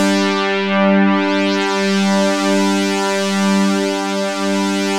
JUP.8 G4   2.wav